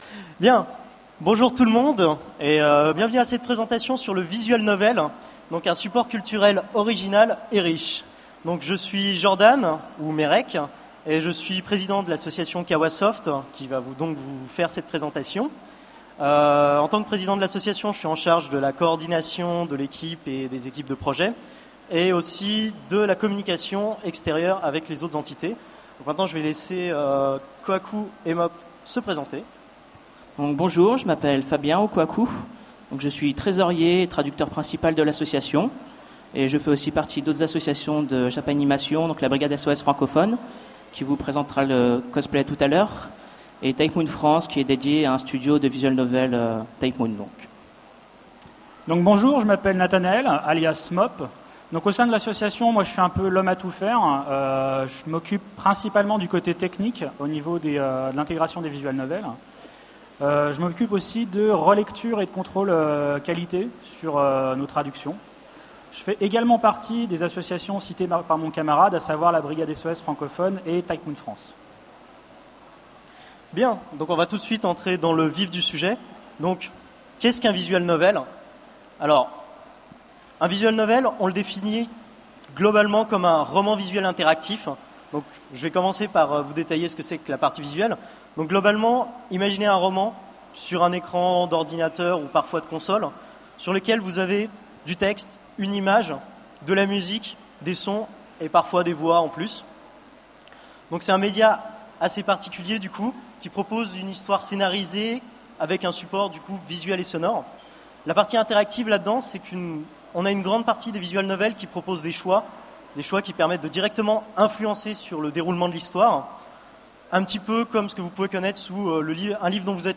Utopiales 13 : Conférence Le Visual Novel, un support culturel original et riche